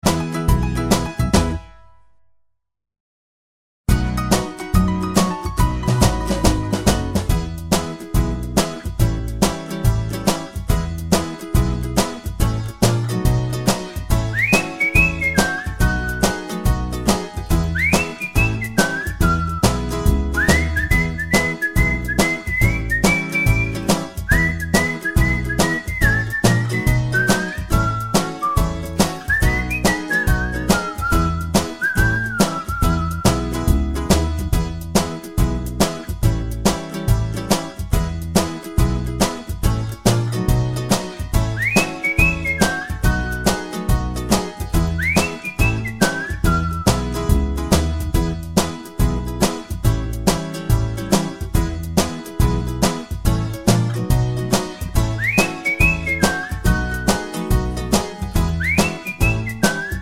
no Backing Vocals no whistle Pop (1950s) 2:28 Buy £1.50